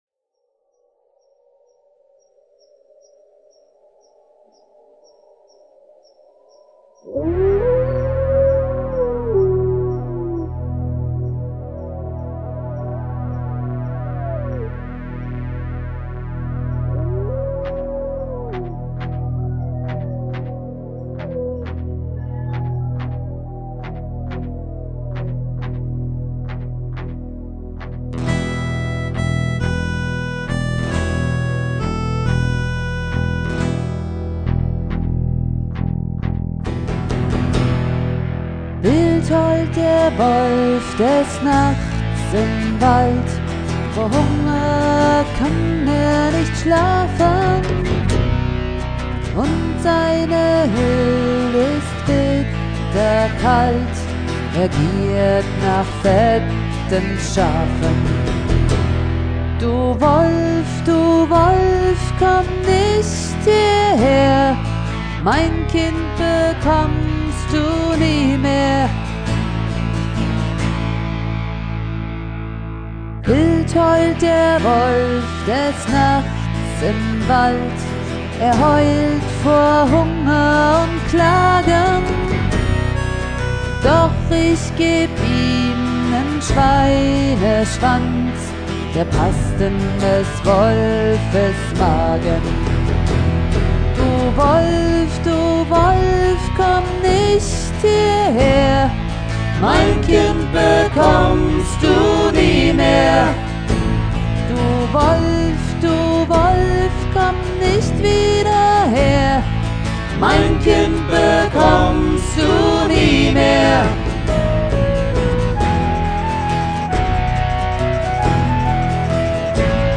Demo
Gesang